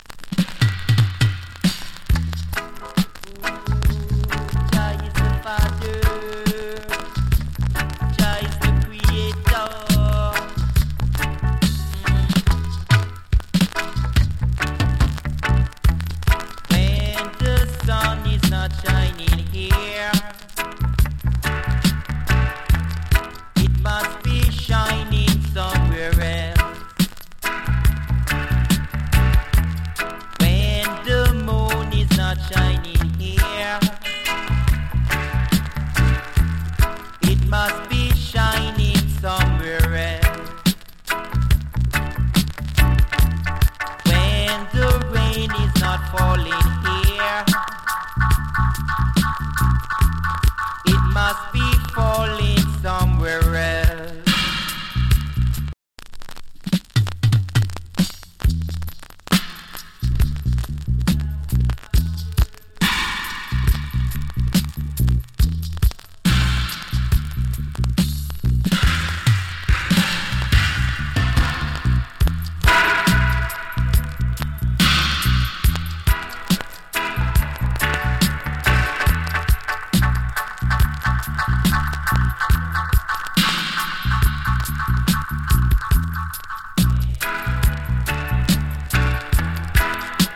＊曲の後半にキズ有り。周期的にノイズ有り。チリ、ジリノイズ多数有り。
EARLY 80'S 初期 DANCE HALL !